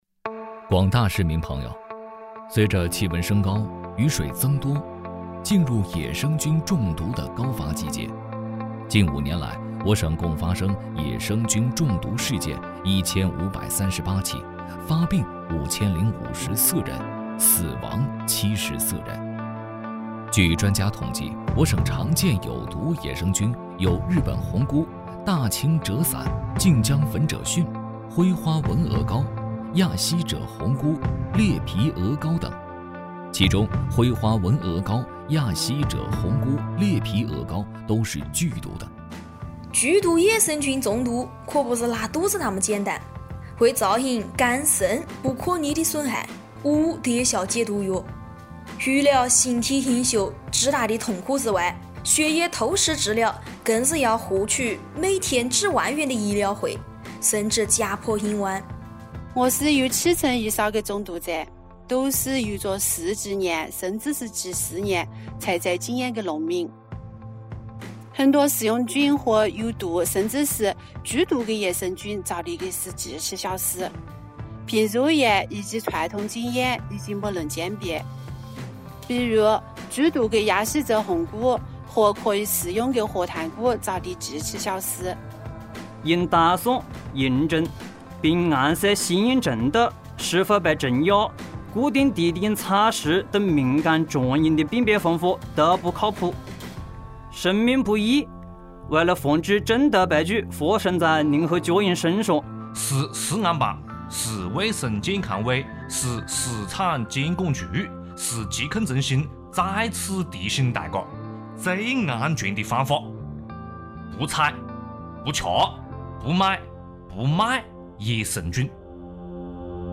方言混剪-不采食食野生菌
方言混剪-不采食食野生菌.mp3